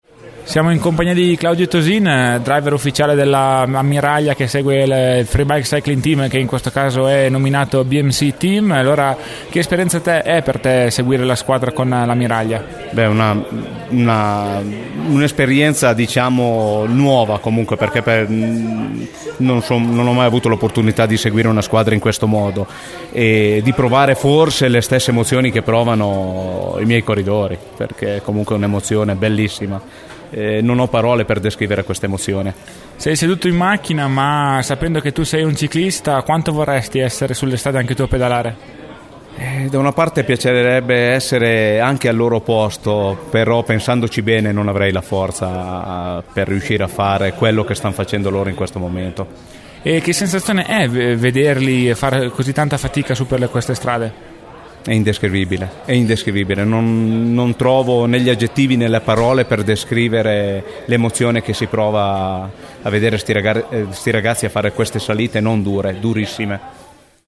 Audio intervista